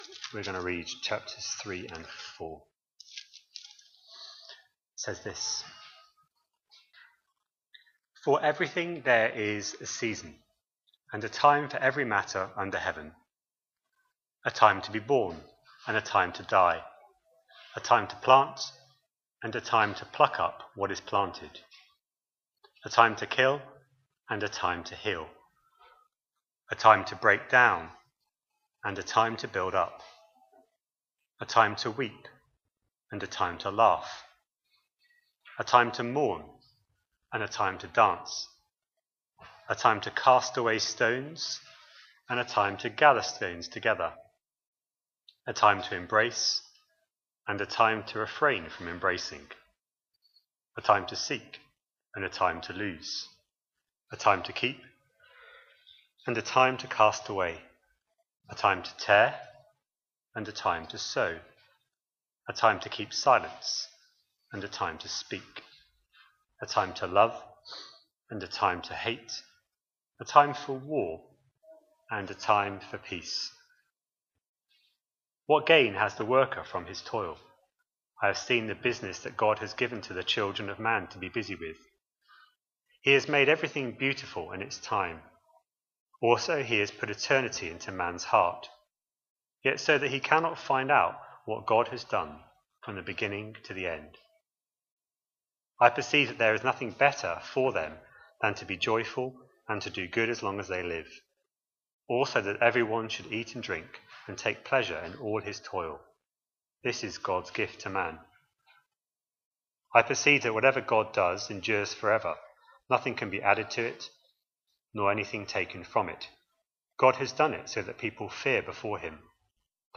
The latest sermons from Trinity Church Bradford.